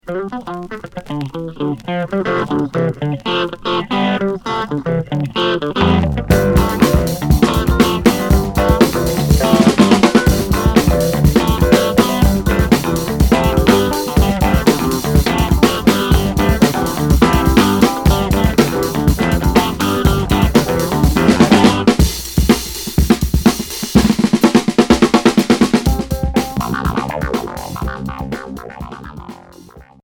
Free pop fusion Unique 45t retour à l'accueil